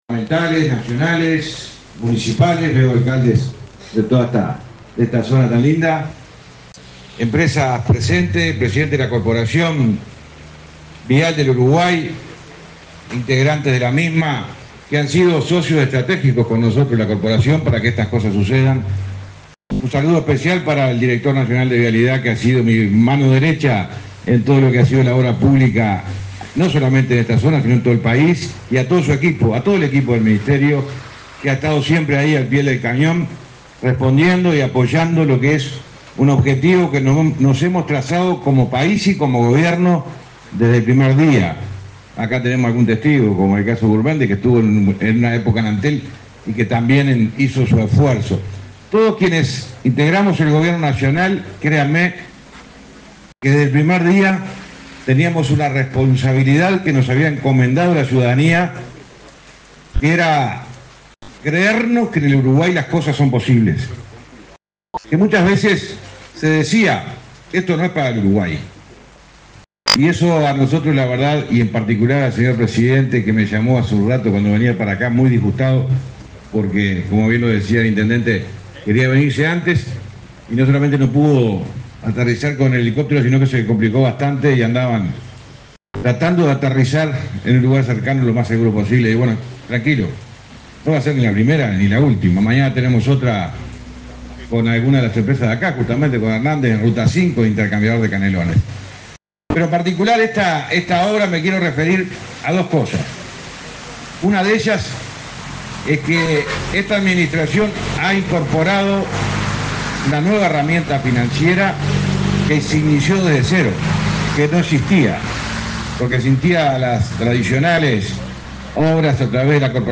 Palabras del ministro de Transporte y Obras Públicas, José Luis Falero
En el evento, disertó el ministro José Luis Falero.